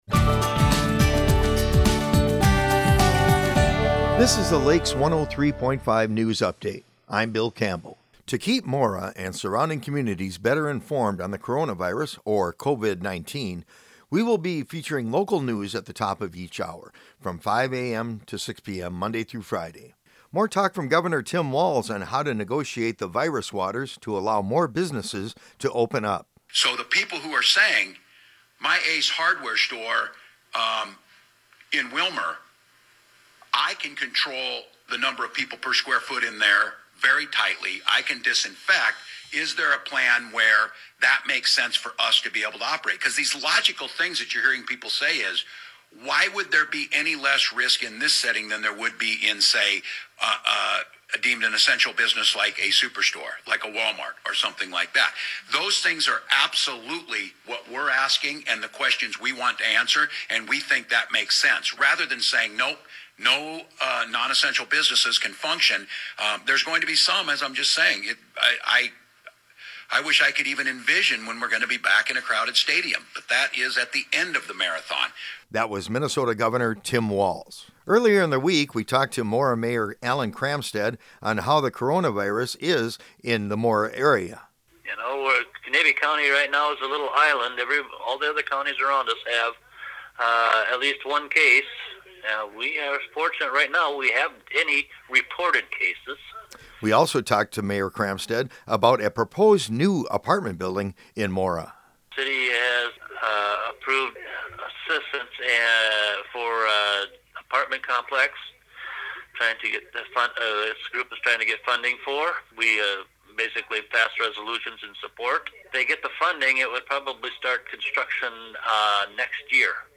This is an archived recording of a feature originally broadcast on Lakes 103.